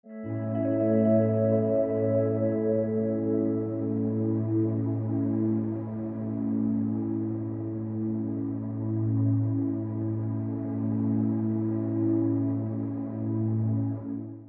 سمپل پد برای فضاسازی | پکیج کامل آکوردهای مینور و ماژور با صدای پد برای فضاسازی و پر کردن زیر صدای خواننده ، مداحی ، دکلمه و هر نوع صدایی که نیاز به بک گراند دارد
demo-pad-moharram.mp3